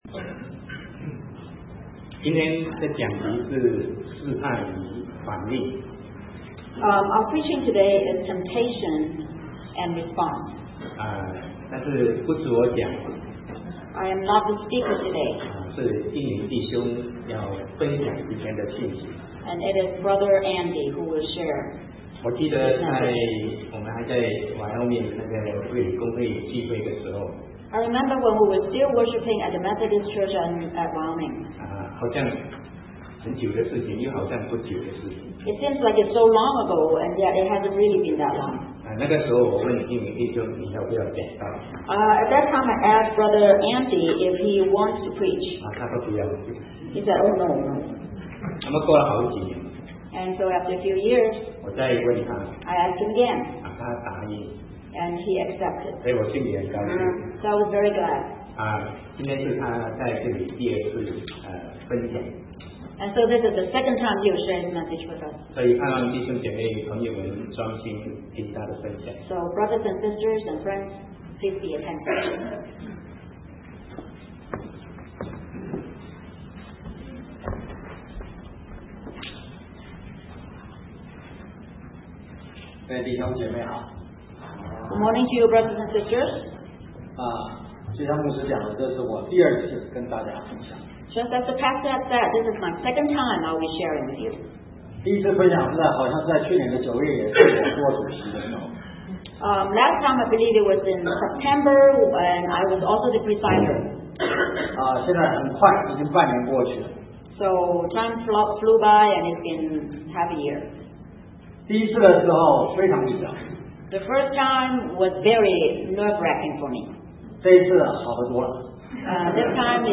Sermon 2010-04-25 Temptation and Response